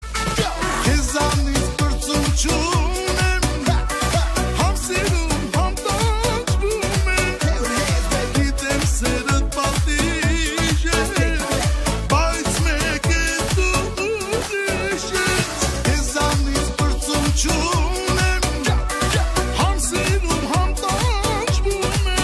• Качество: 320, Stereo
поп
мужской вокал
восточные мотивы
dance
армянские